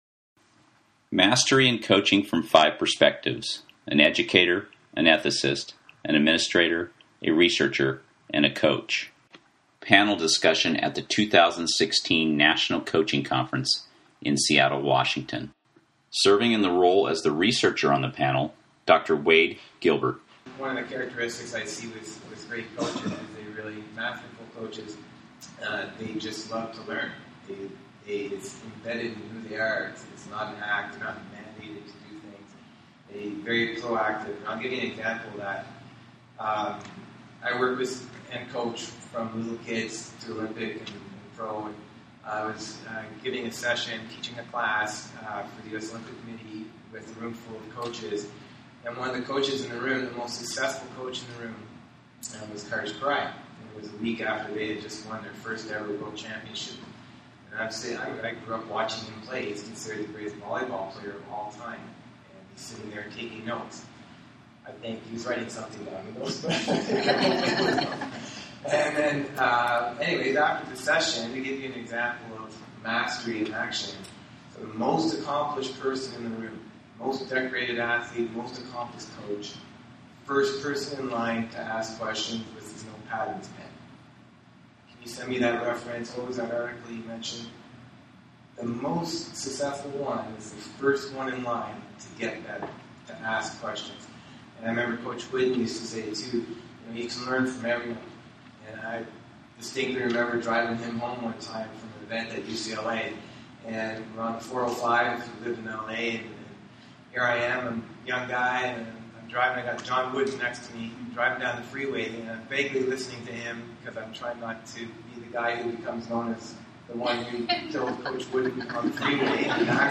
During the 2016 National Coaching Conference in Seattle, WA, a panel of five experts in their respected fields shared insight on pursuing mastery as a coach.